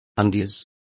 Complete with pronunciation of the translation of undies.